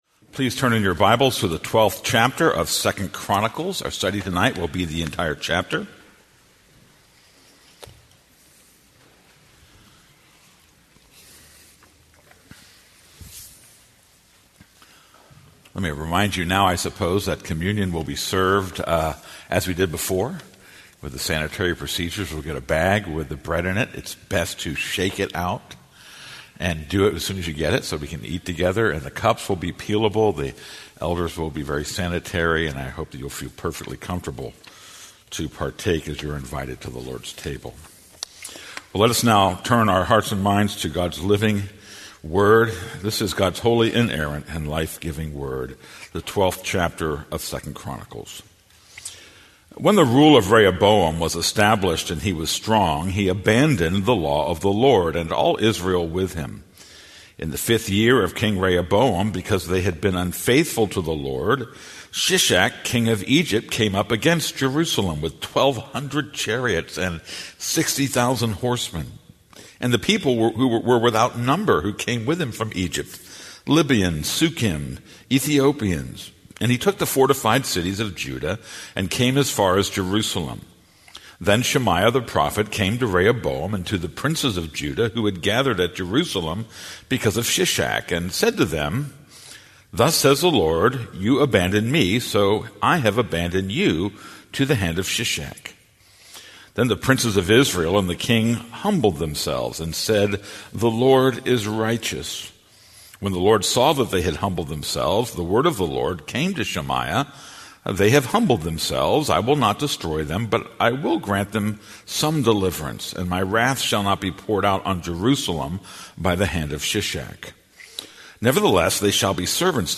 This is a sermon on 2 Chronicles 12:1-16.